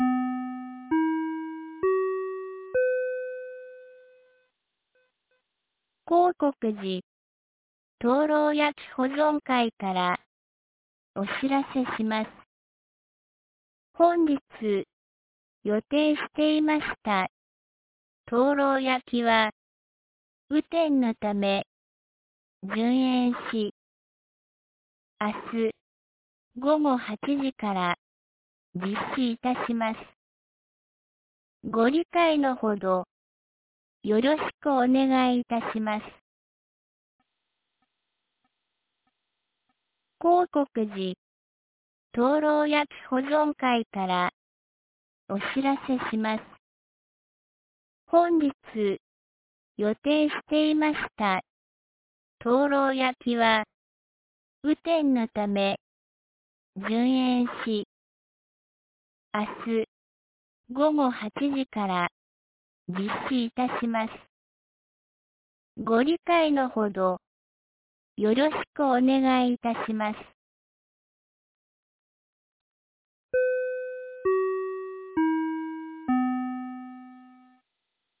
2024年08月15日 19時47分に、由良町から全地区へ放送がありました。